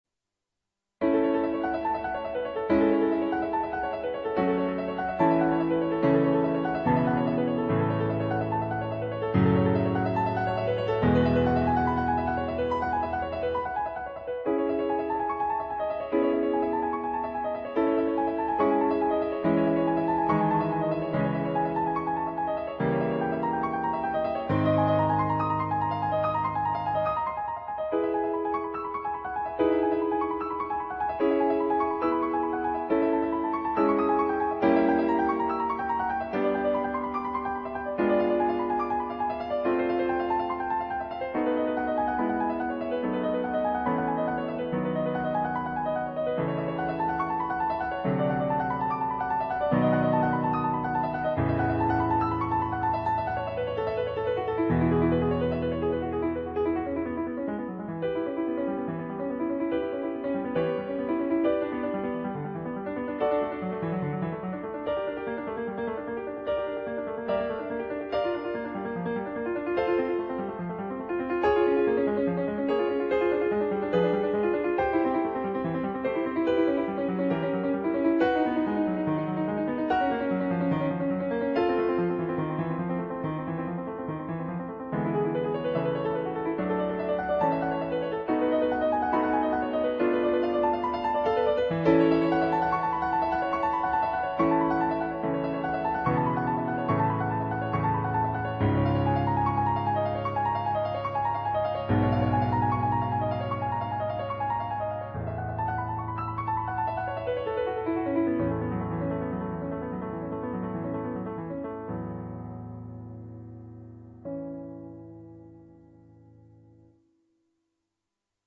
28. in A Major (1'58")
on Yamaha digital pianos.